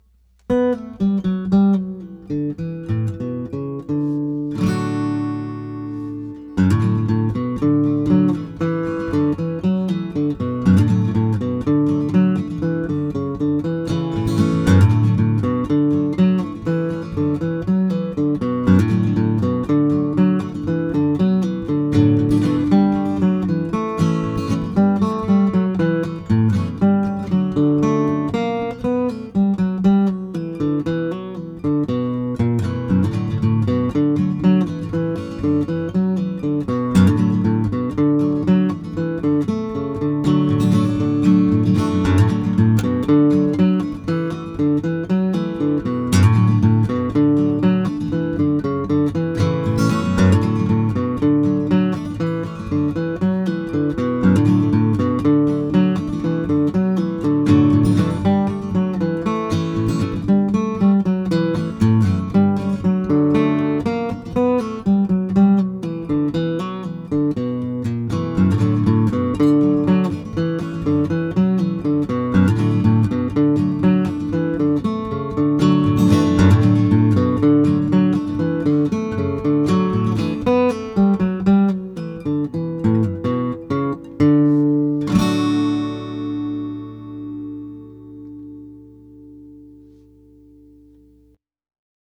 However, it wasn’t until I added an intro/outro lick sometime last Summer that my compositon finally felt complete.
But this past Sunday – March 6, 2016 – I decided it was time to capture my “latest” original acoustic guitar instrumental in zeros and ones.